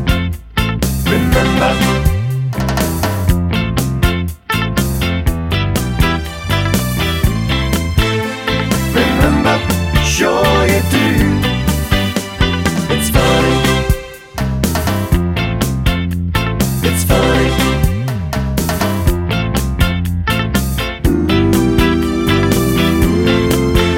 no Backing Vocals Soul / Motown 3:31 Buy £1.50